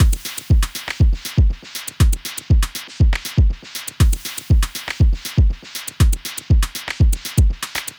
120_FullDelaysBeats_TL_01.wav